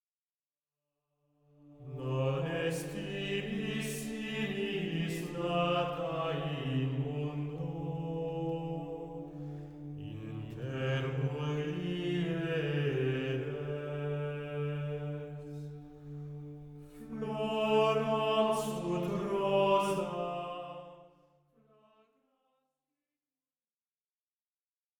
Antienne mariale